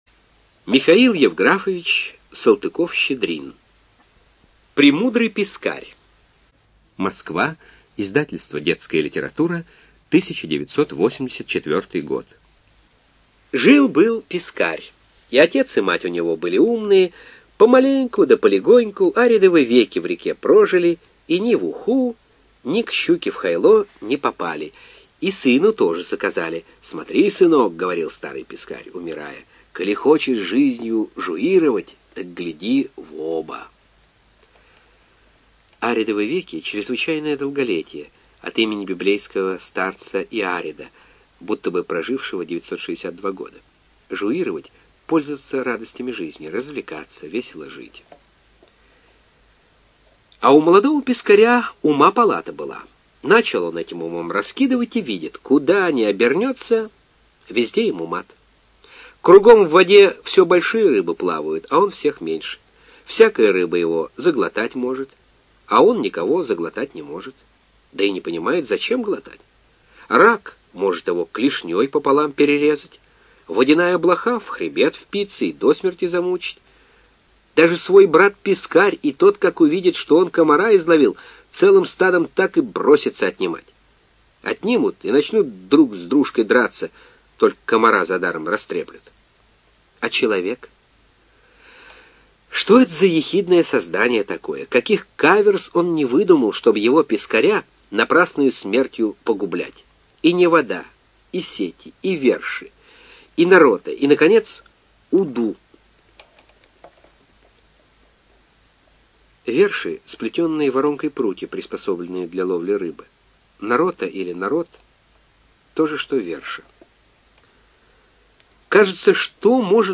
ЖанрДетская литература, Классическая проза
Студия звукозаписиЛогосвос